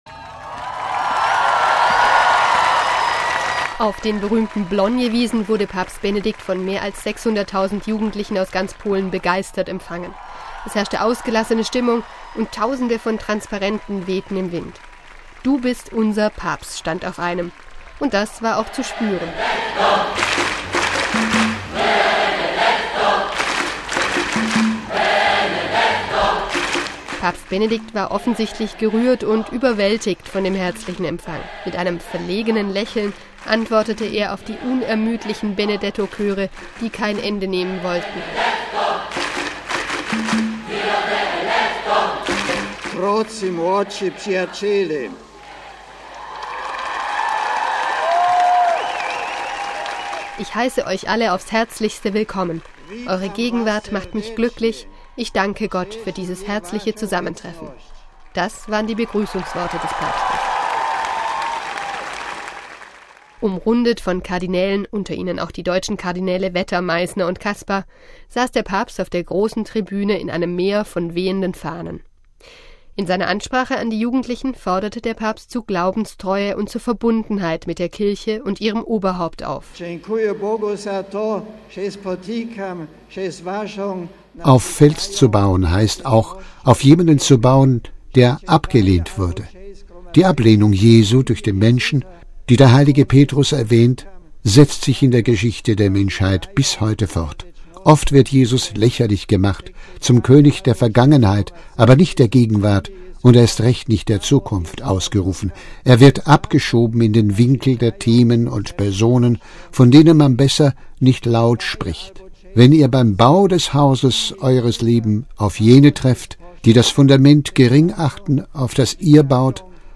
MP3 Auf den berühmten Blonie Wiesen in Krakau wurde Papst Benedikt am Samstag Abend von mehr als 600 000 Jugendlichen aus ganz Polen begeistert empfangen. Es herrschte ausgelassene Stimmung.
Mit einem verlegenen Lächeln antwortete er auf die unermüdlichen Benedetto Chöre, die kein Ende nehmen wollten.
Minutenlanger Applaus war die Antwort der Jugendlichen auf die Erwähnung des verstorbenen Papstes.